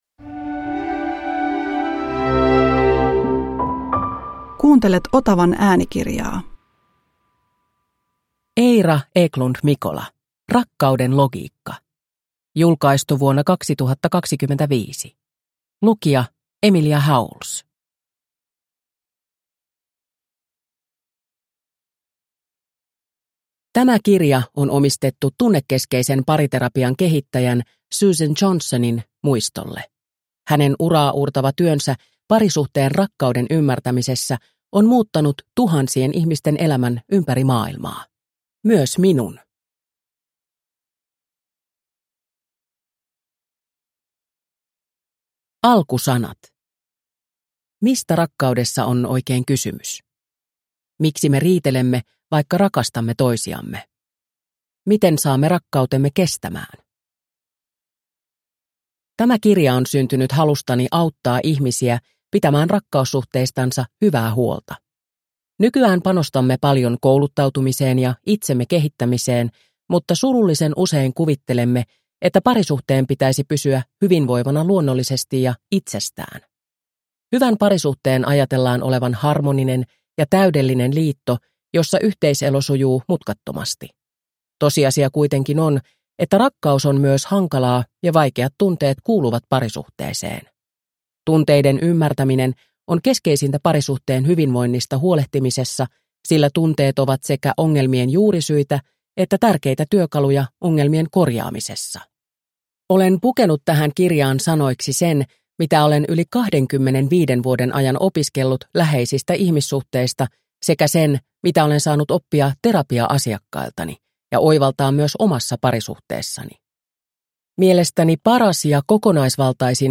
Rakkauden logiikka – Ljudbok